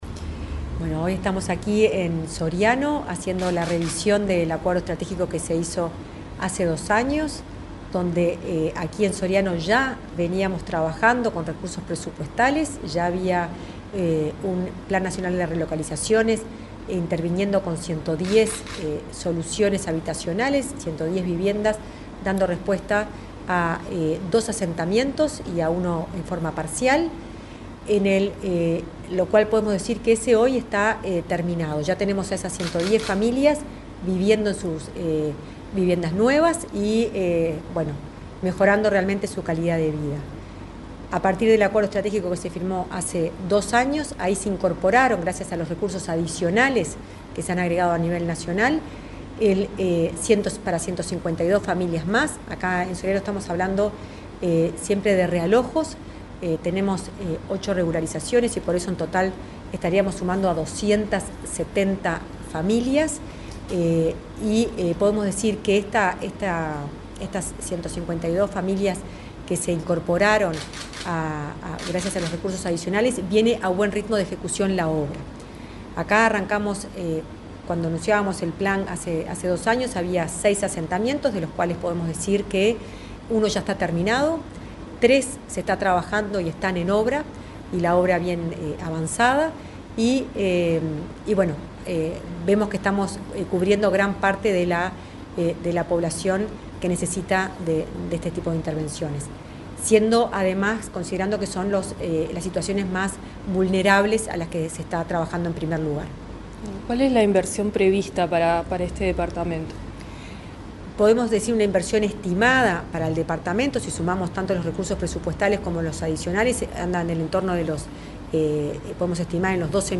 Entrevista a la directora de Integración Social y Urbana del MVOT, Florencia Arbeleche
La directora de Integración Social y Urbana del Ministerio de Vivienda y Ordenamiento Territorial (MVOT), Florencia Arbeleche, dialogó con Comunicación Presidencial en Soriano, donde la jerarca participó en una revisión de los acuerdos estratégicos del plan Avanzar, junto con el equipo técnico de la intendencia local.